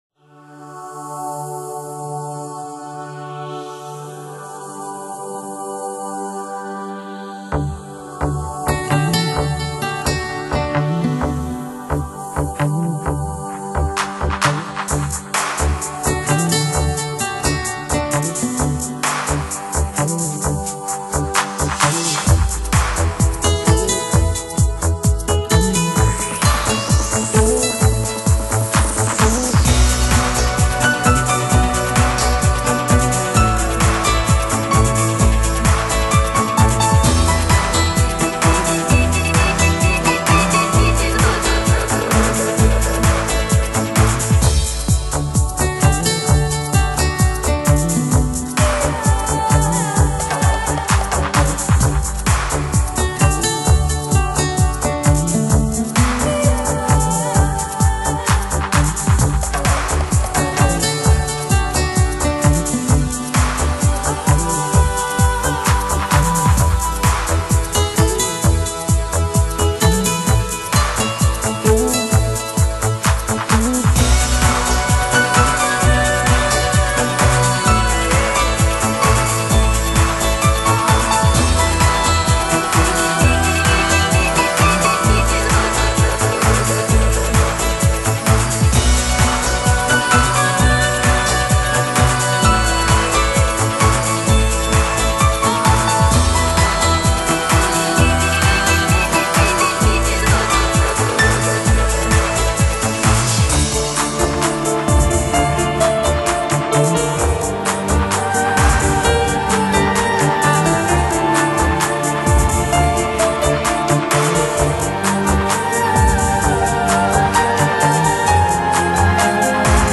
强悍、温和的电子节拍贯穿整张专辑
电子合成乐器、水晶吉他及钢琴以轻缓却持续的节奏融合进曲调中
他们用艺术味浓重的风格打造出这张颇具幻想色彩的专辑。